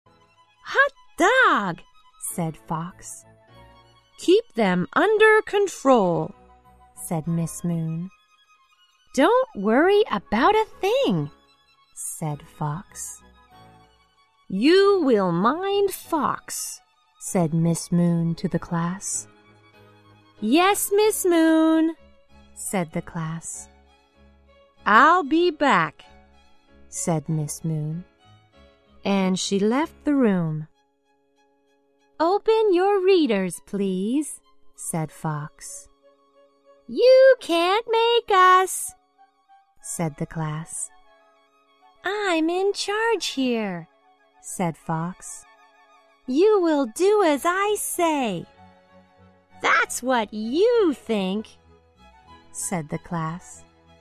在线英语听力室小狐外传 第39期:真酷的听力文件下载,《小狐外传》是双语有声读物下面的子栏目，非常适合英语学习爱好者进行细心品读。故事内容讲述了一个小男生在学校、家庭里的各种角色转换以及生活中的趣事。